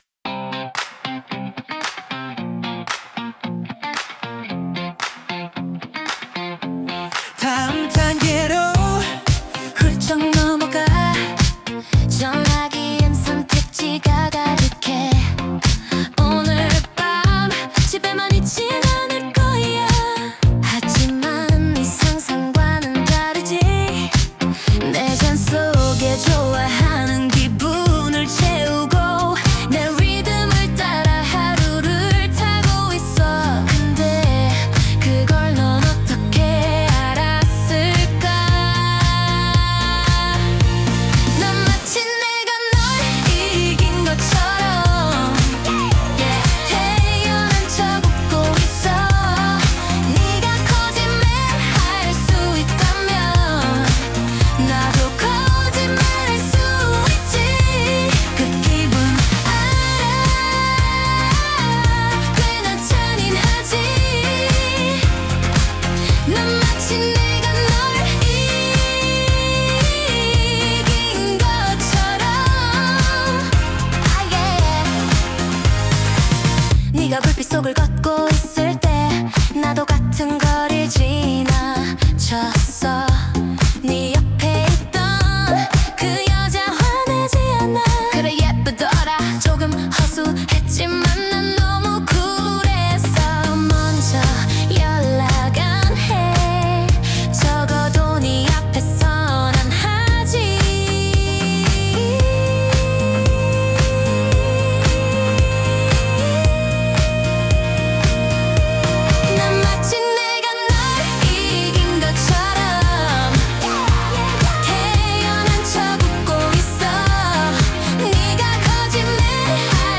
허락을 구한 후 가사만 개사해서 리믹스 한 작품입니다.
#K-pop